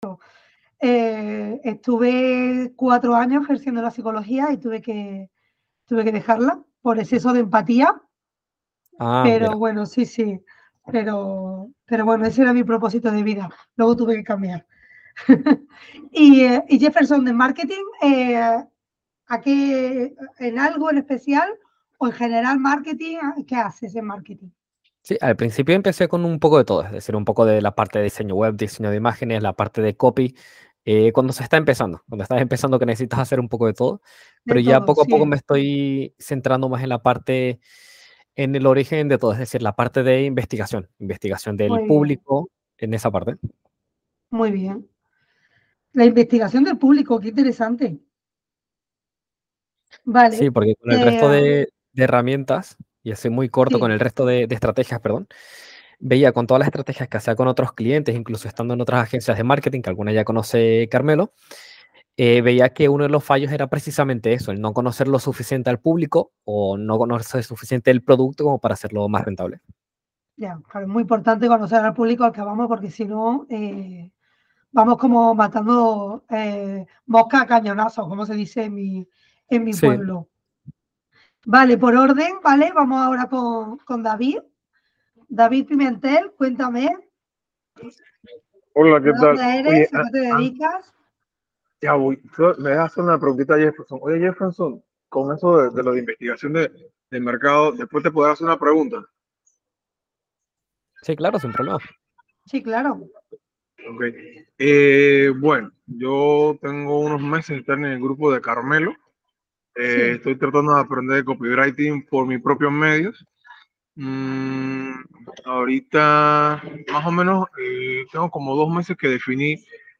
¿Cuál es el papel que teine el networking en el negocio de un copywriter? Te lo cuento en esta masterclass gratuita.